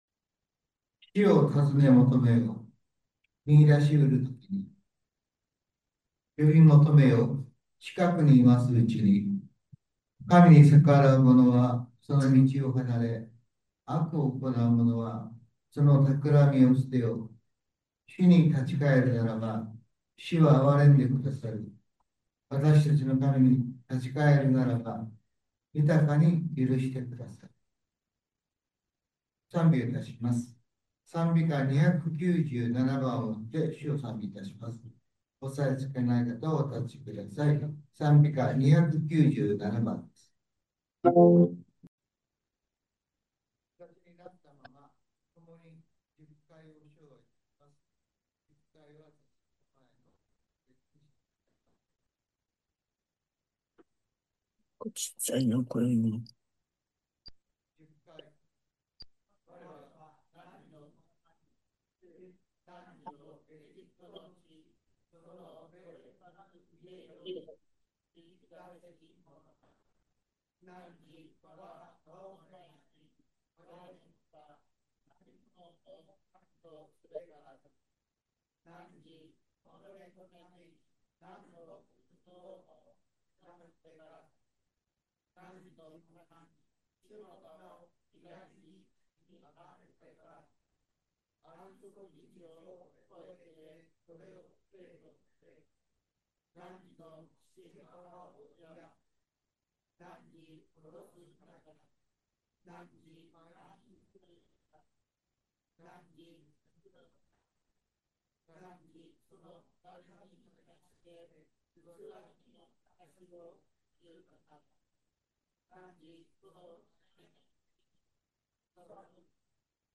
３月８日（日）主日礼拝